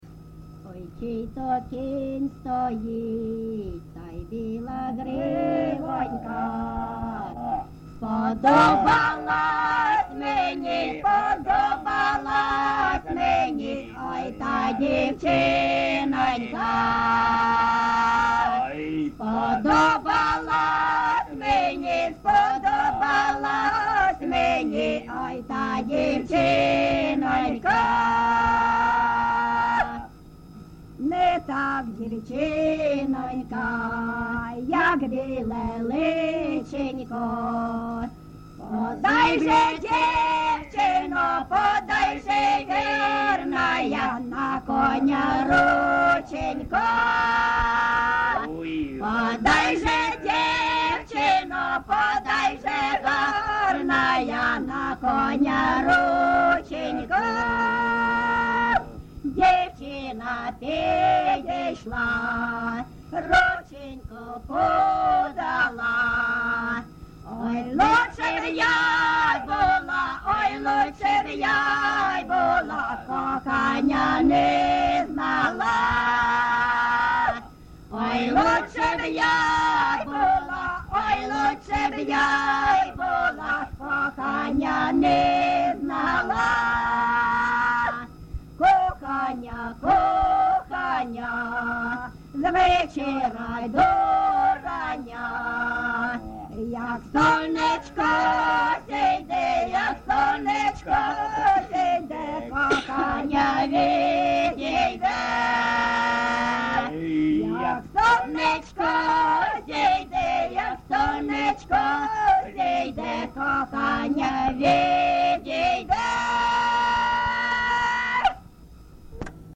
ЖанрПісні з особистого та родинного життя
Місце записус. Свято-Покровське, Бахмутський район, Донецька обл., Україна, Слобожанщина